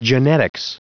Prononciation du mot genetics en anglais (fichier audio)
Prononciation du mot : genetics